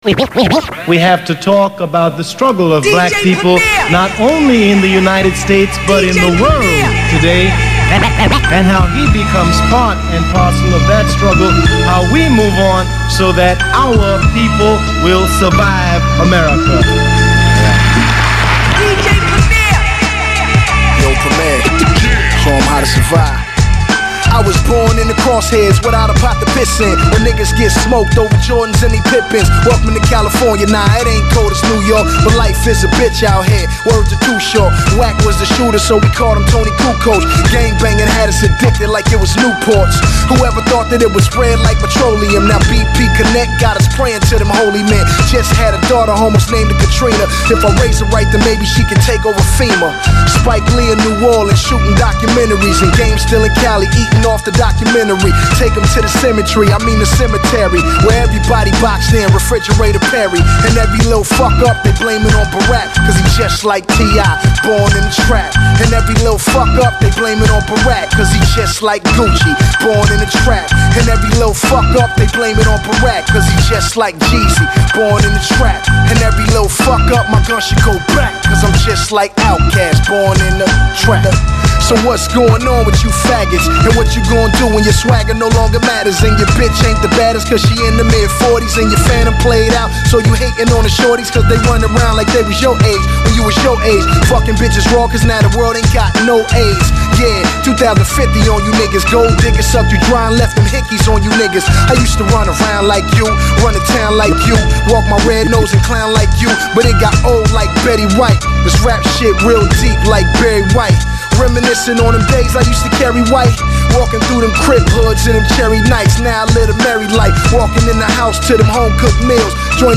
(RADIO RIP)